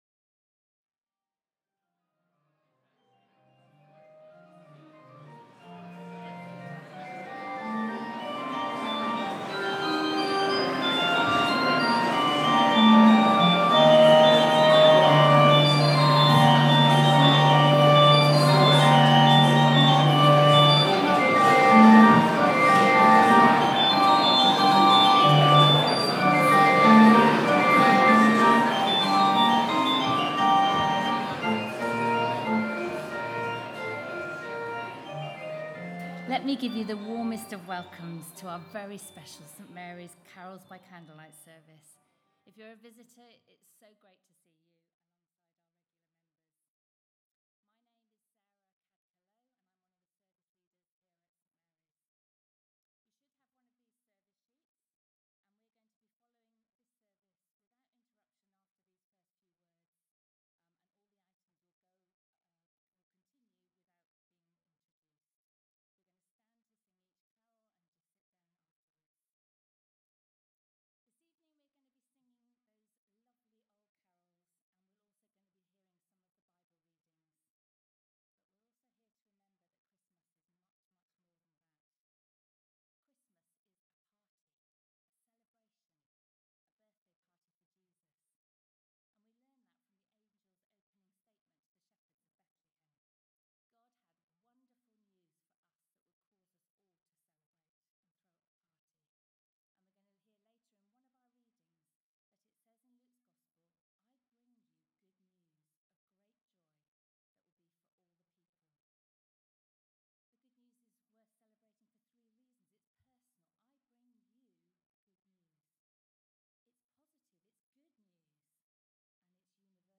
Celebration Service